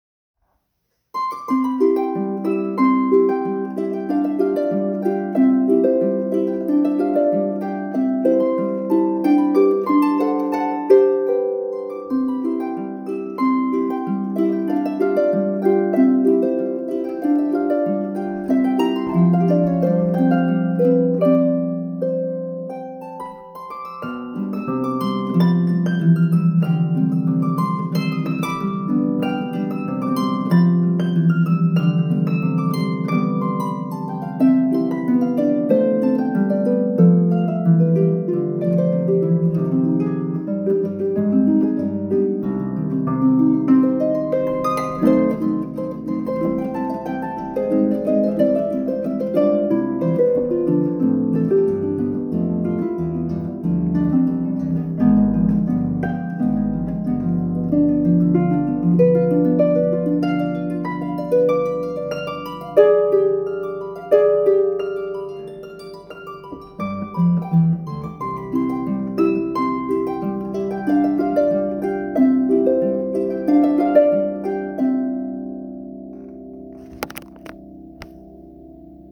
Pedal Harp